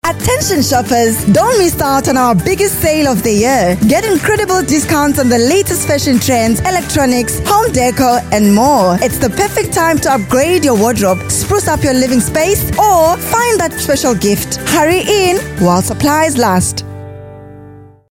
articulate, authoritative, bright, commercial, confident, conversational, energetic, informative
Her voice has a unique or rare natural warmth, that can capture the attention of the audience.
Hard Sell